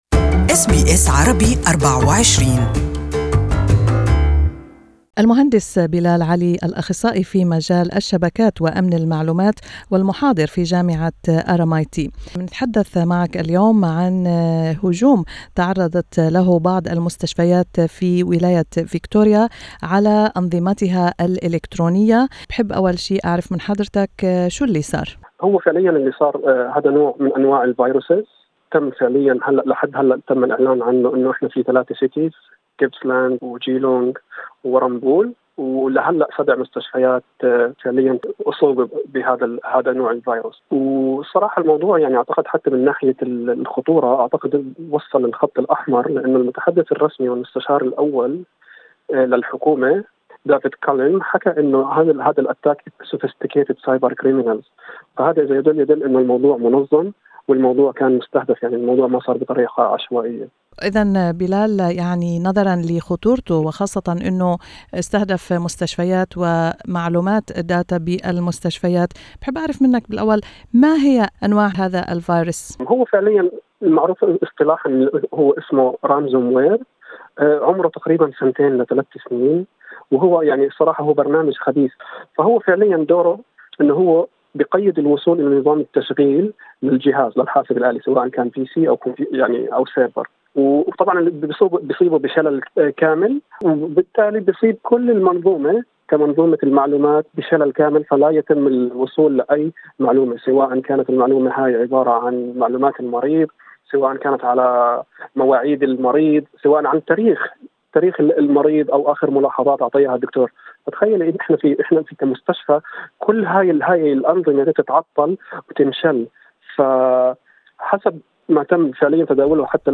An interview in Arabic with IT expert